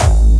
VEC3 Bassdrums Dirty 24.wav